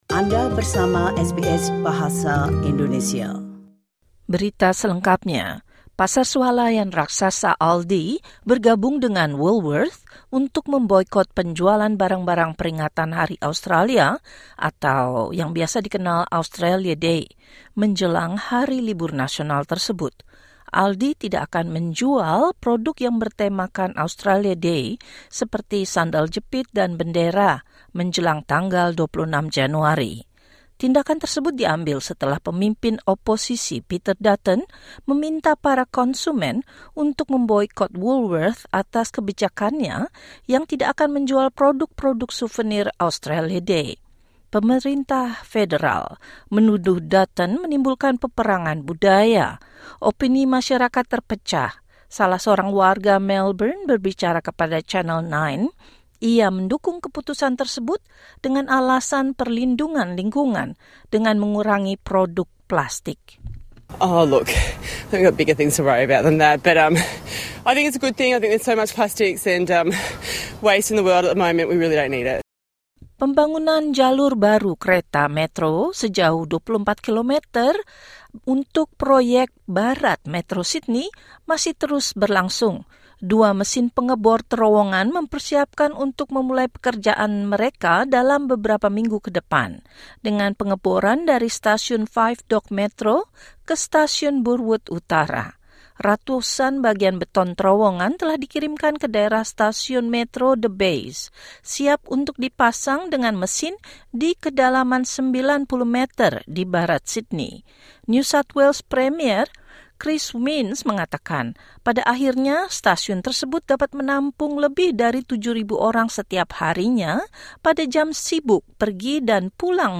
Berita terkini SBS Audio Program Bahasa Indonesia – 12 Januari 2024